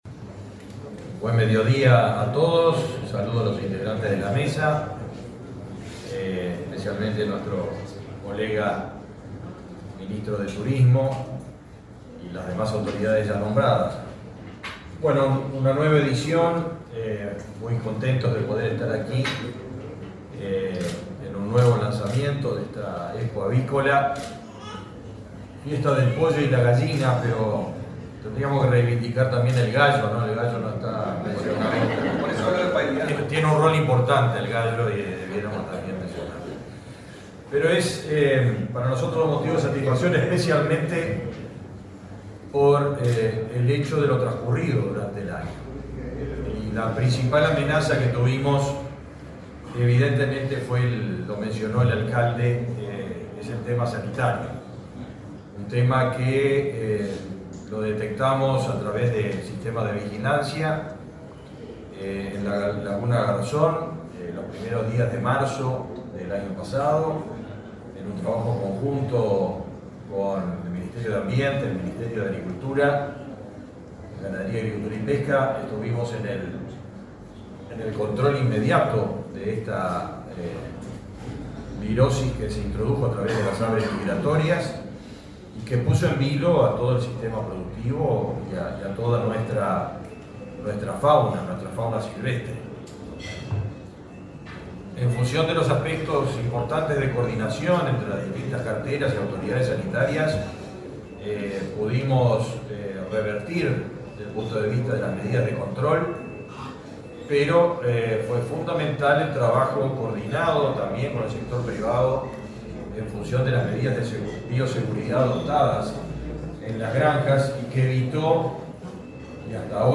Palabra de autoridades en lanzamiento de Fiesta del Pollo y la Gallina
El ministro de Ganadería, Fernando Mattos, y su par de Turismo, Tabaré Viera, participaron, este lunes 15 en Montevideo, del lanzamiento de la 12.ª